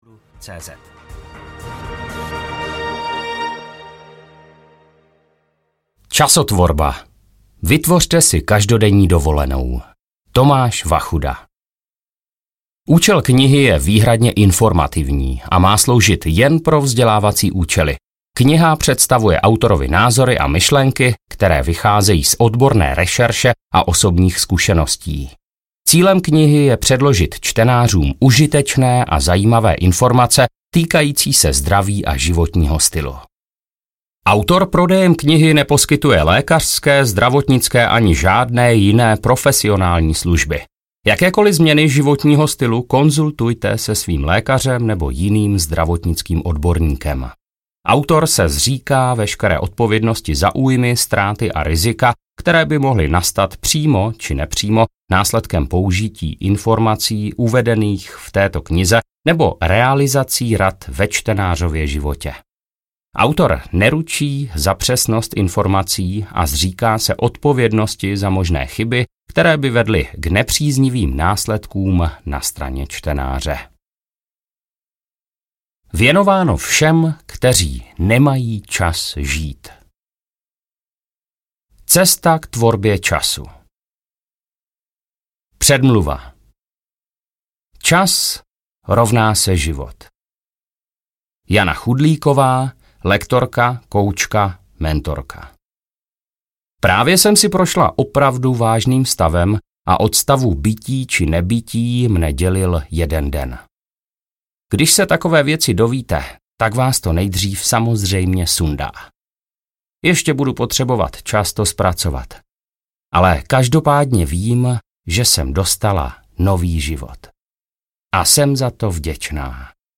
Časotvorba audiokniha
Ukázka z knihy